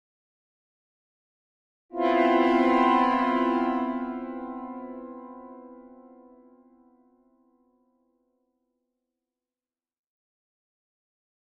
Symphonic Strings Suspense Chord 4 - Higher